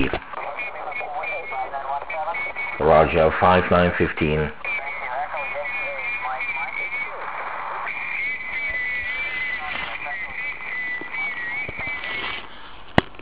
Některé stanice poslouchali docela dobře: